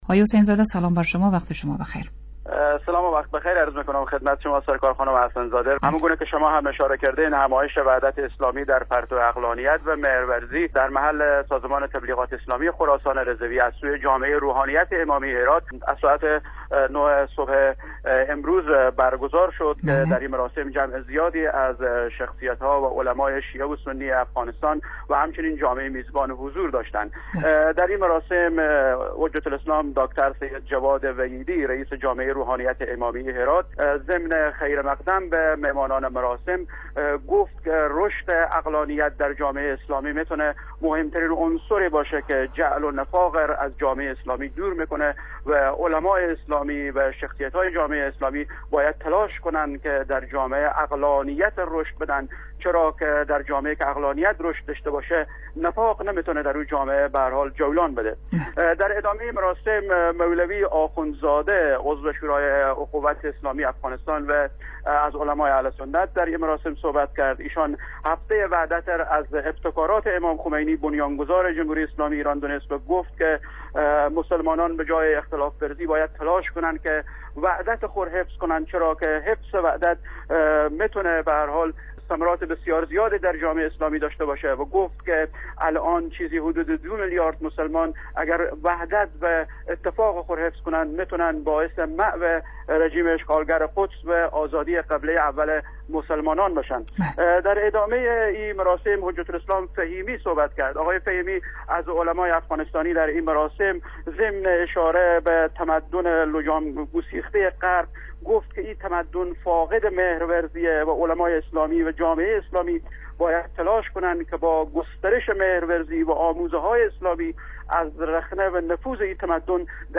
به مناسبت هفته وحدت، همایش وحدت اسلامی در پرتو عقلانیت و مهرورزی با حضور جمع زیادی از علما و شخصیت های شیعه و سنی از سوی جامعه روحانیت امامیه هرات در مشهد...